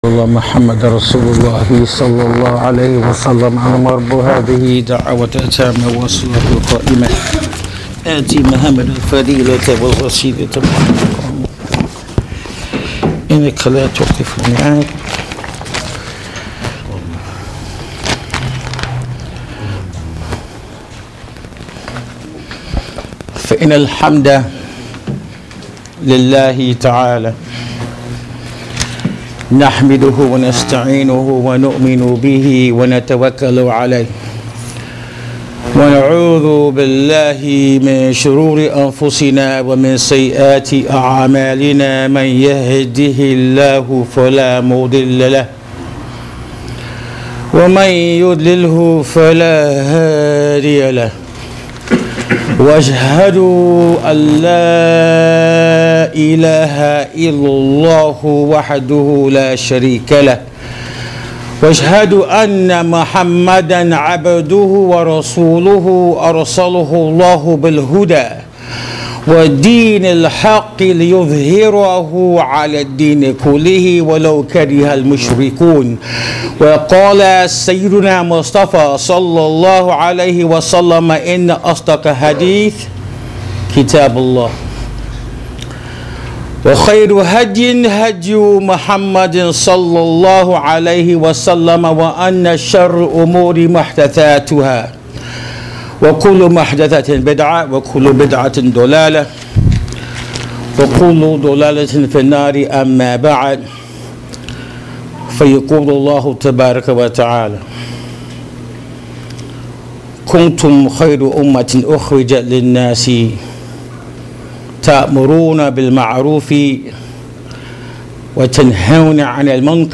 This is the topic of this important khutbatul Jum’ah recorded at Masjid Ibrahim Islamic center in Sacramento, Ca. Click on the link below to take a listen.